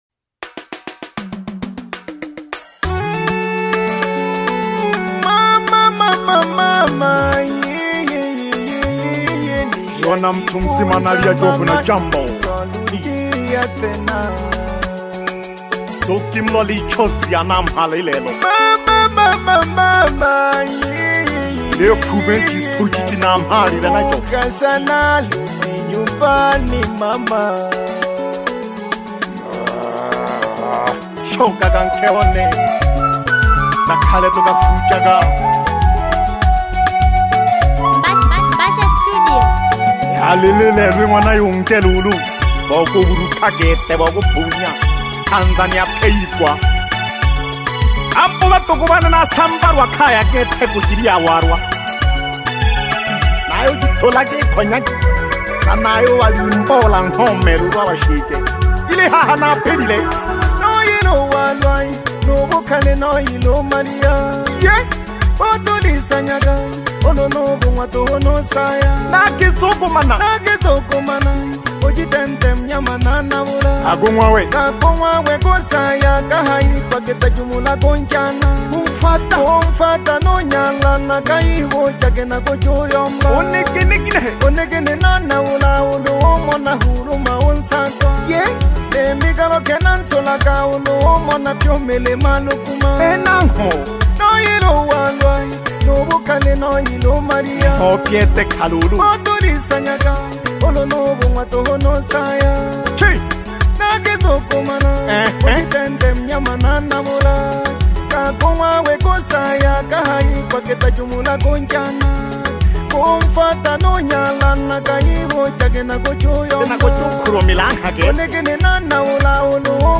Asili Celebration music
Celebration Asili music track